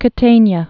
(kə-tānyə, -tānē-ə, kä-tänyä)